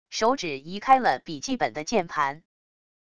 手指移开了笔记本的键盘wav音频